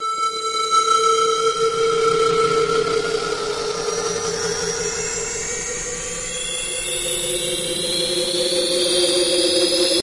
描述：这又是我为音乐课做的一首歌的一部分，不是整首歌，而是一个片段......这只是我做的噪音......纯粹的噪音，然后再加上一些其他的垃圾......这是第一首歌
标签： 气氛 环境 恼人的 扭曲的 响亮 噪声 音调
声道立体声